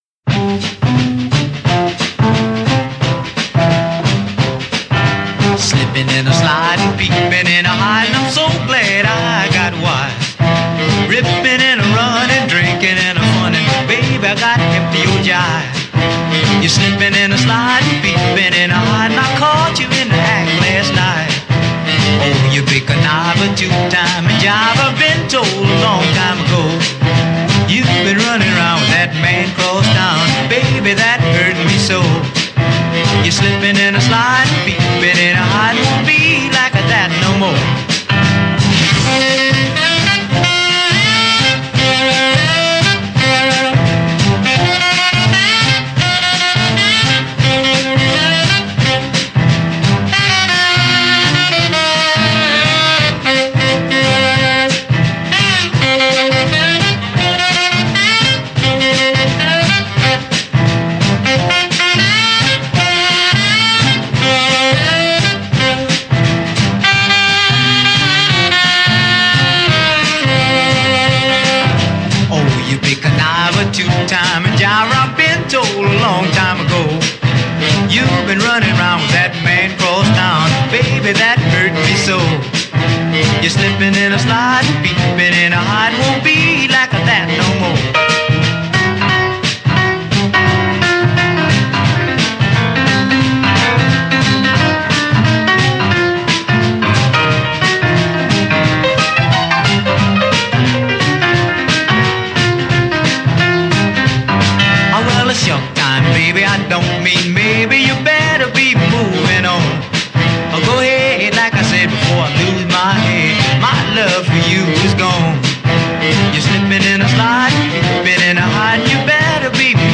an earlier, slower rhythm and blues version